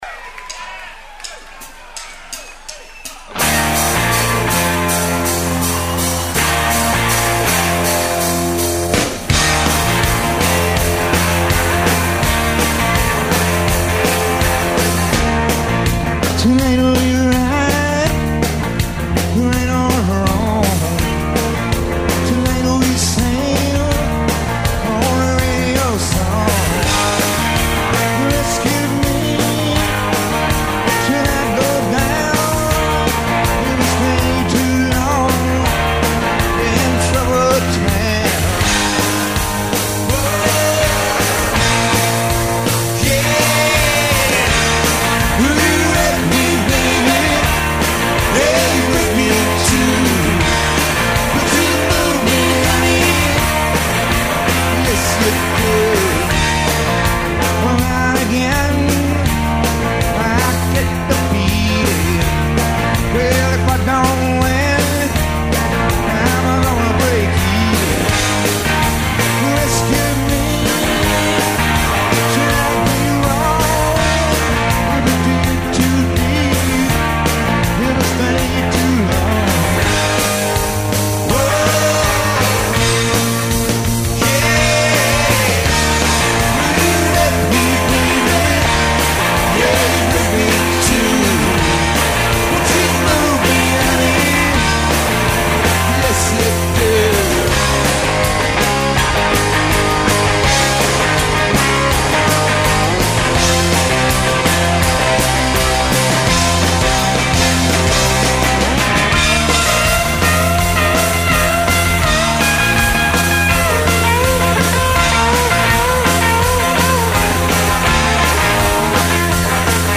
three simple descending chords and a red hot live band